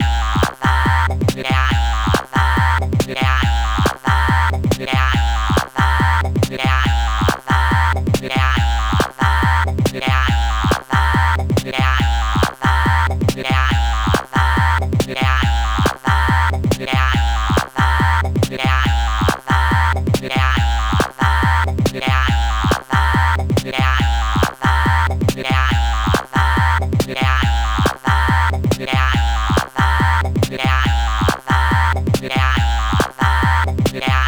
ご用意したのは、ステレオの一般的なループ音源と、POシリーズに同期をして楽しめるクリック入りのシンクモードのループ音源です。
ボーカル・シンセサイザー
BPM 140（TECHNOモード）